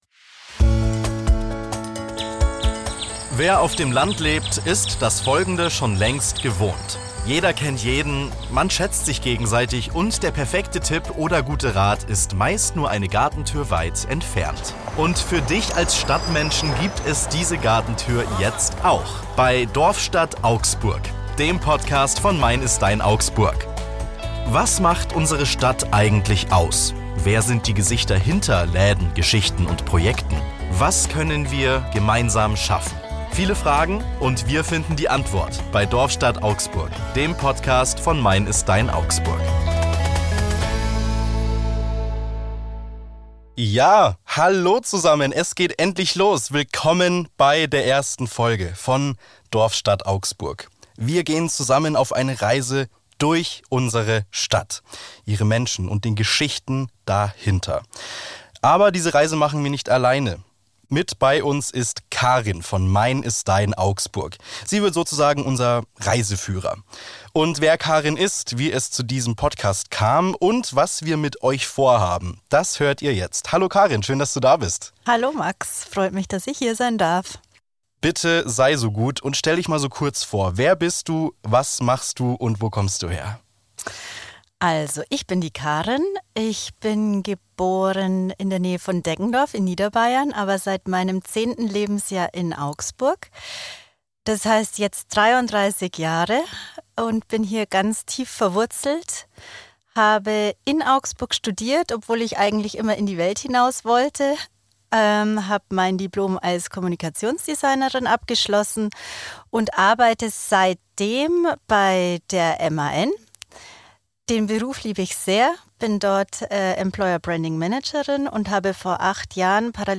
im Studio